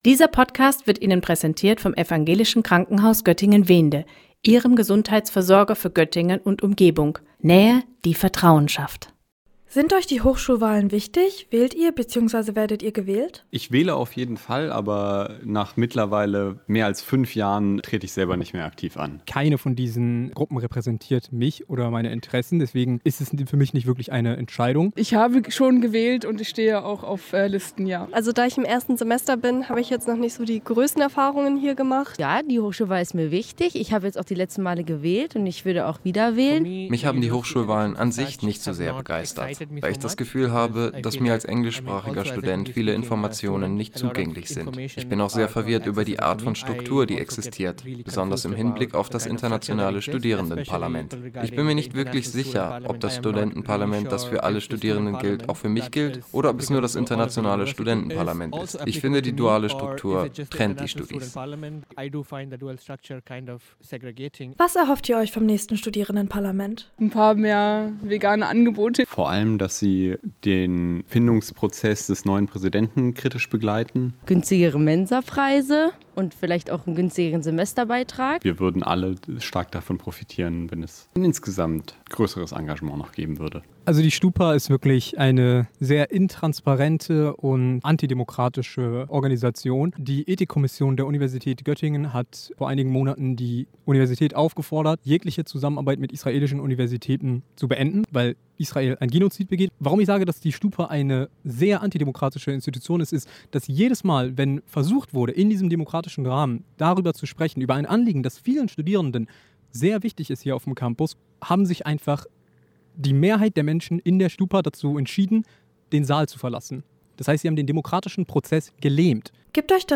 Sendung: Umfragen Redaktion